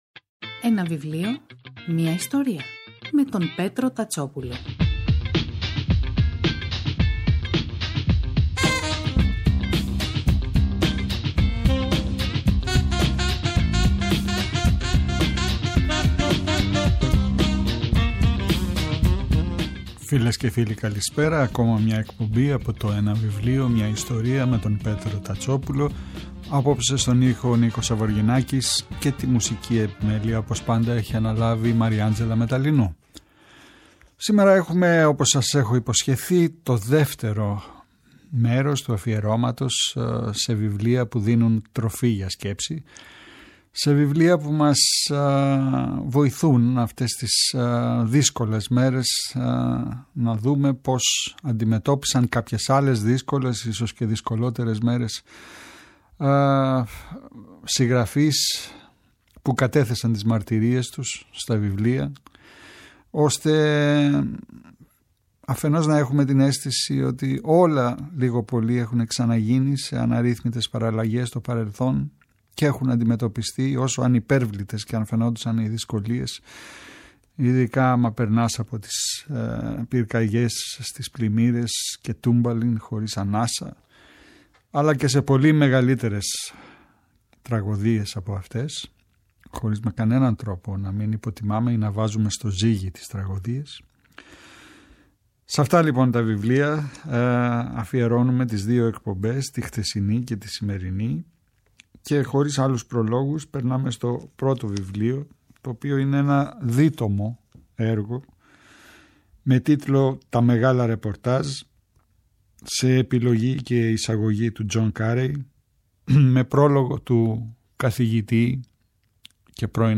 Στη ραδιοφωνική εκπομπή μου “Ένα βιβλίο, μια ιστορία”.